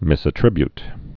(mĭsə-trĭbyt)